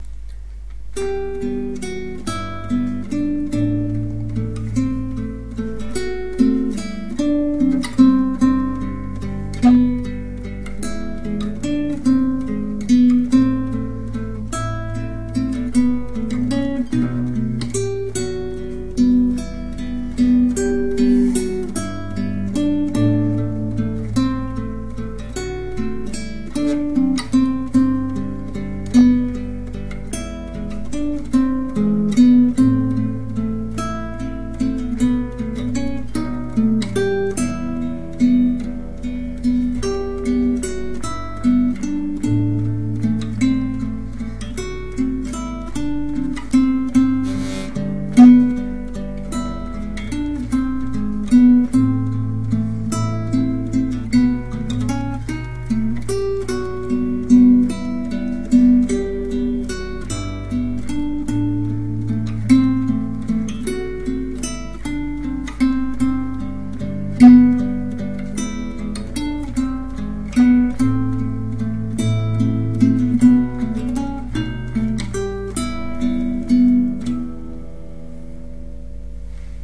Инструментальная пьеса Без слов